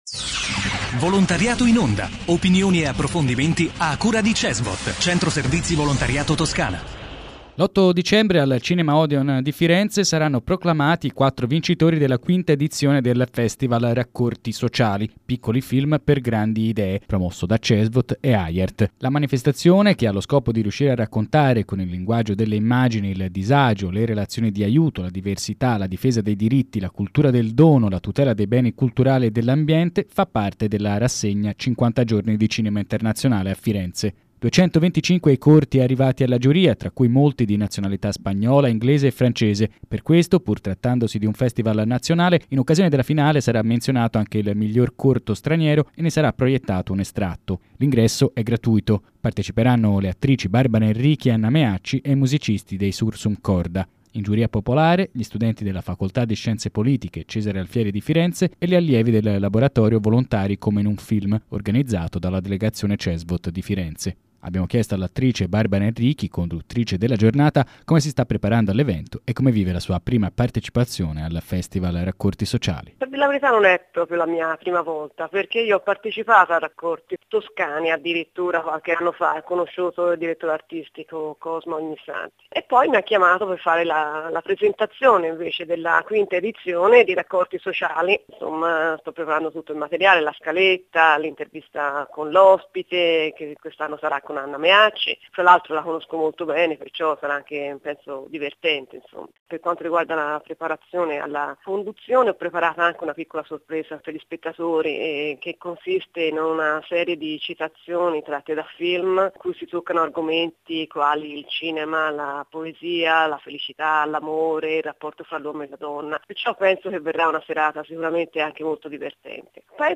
Intervista all'attrice Barbara Enrichi, presentatrice di Raccorti sociali 2013